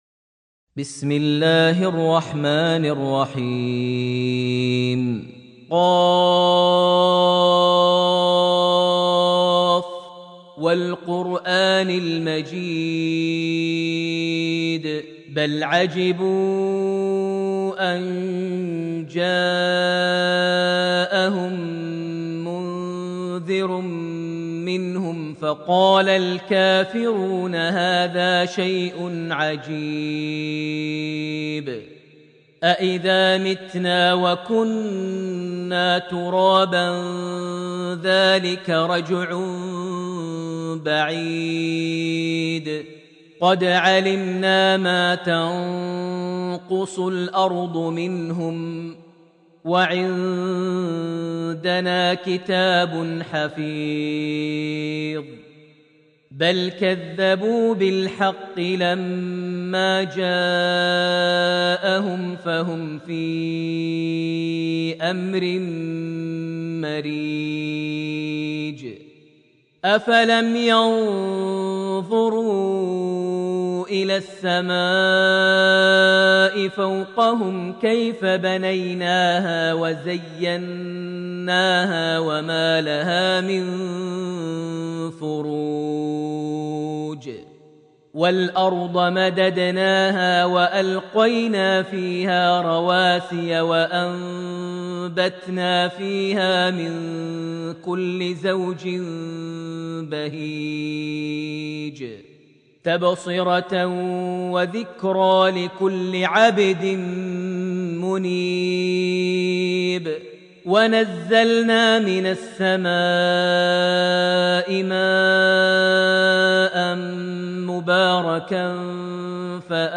Surah Qaf > Almushaf > Mushaf - Maher Almuaiqly Recitations